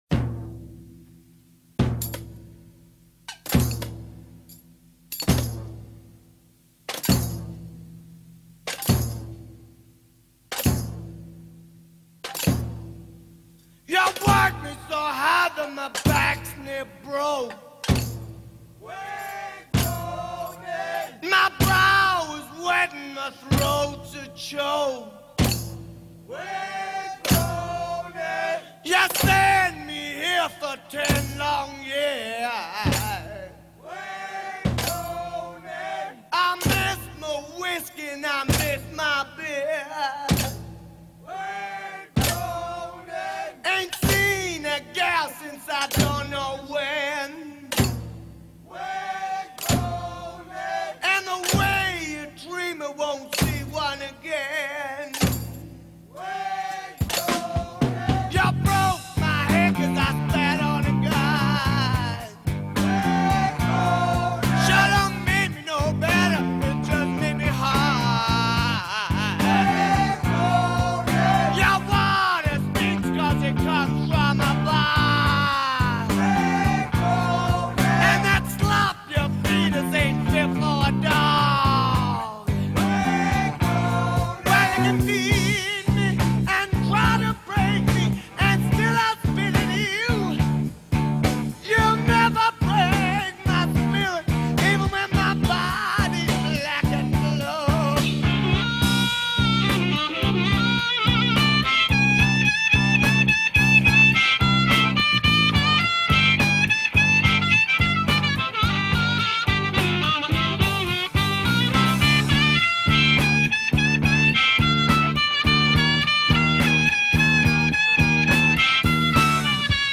Descarga directa Esta semana te traemos el mejor Blues desde Australia.